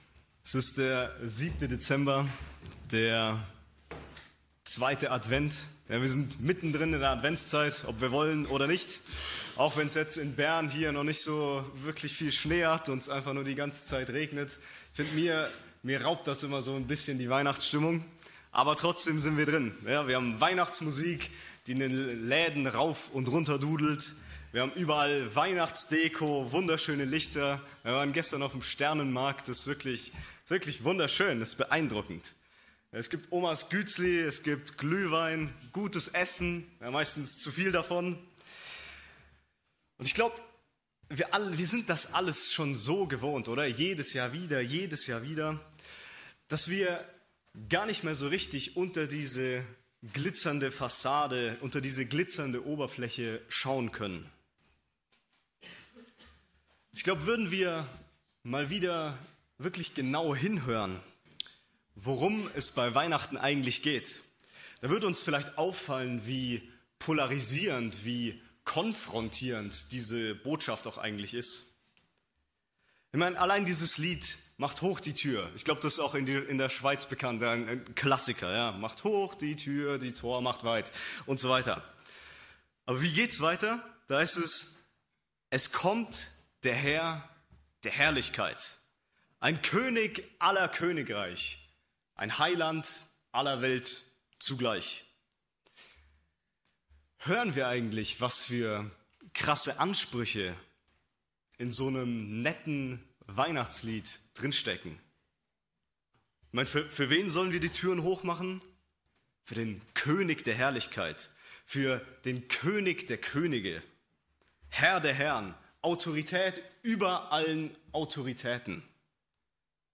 Kategorie: Adventsgottesdienst